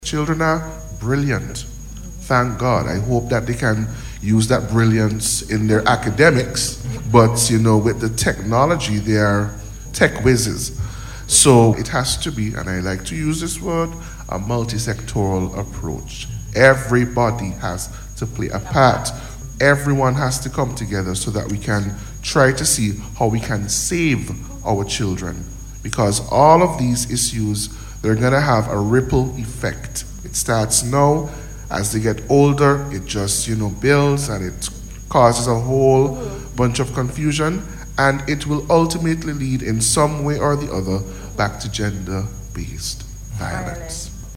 He made the point during his presentation at a Panel Discussion hosted recently by the Gender Affairs Division within the Ministry of National Mobilization.